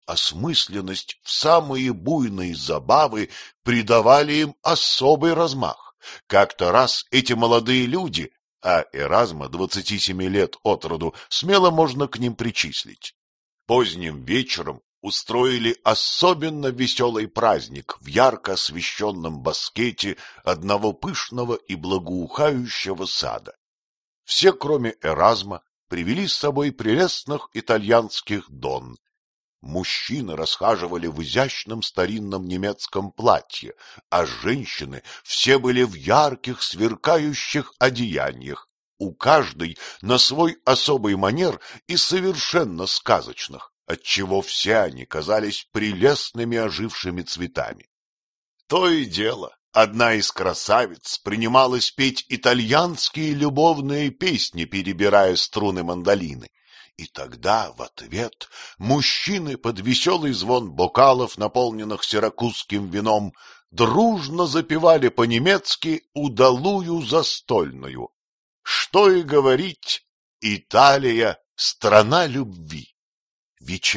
Аудиокнига История о пропавшем отражении | Библиотека аудиокниг